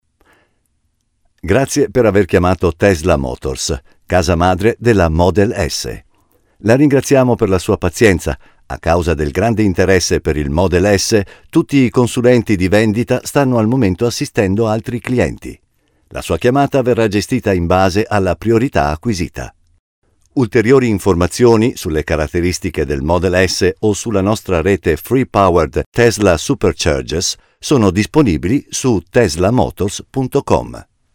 Telefonansage Italienisch (CH)
Tessin